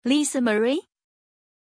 Pronunciation of Lisamarie
pronunciation-lisamarie-zh.mp3